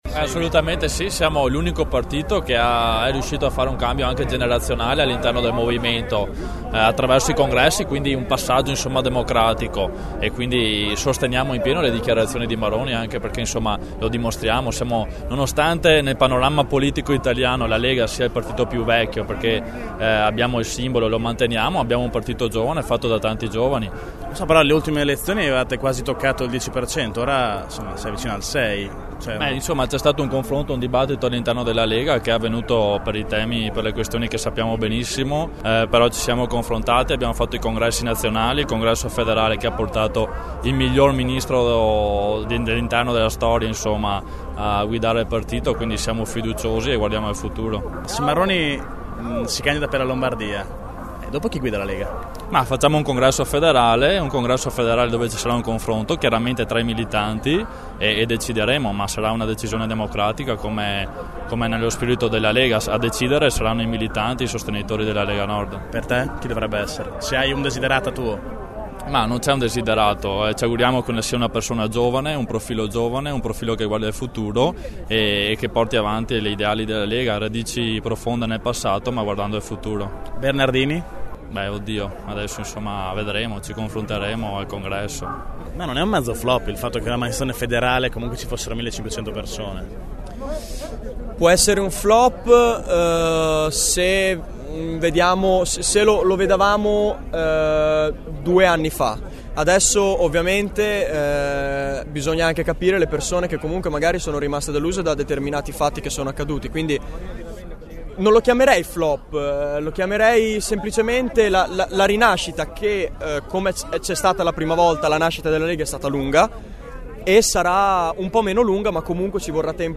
Queste alcune delle voci raccolte tra i militanti
Militanti-Lega_sito.mp3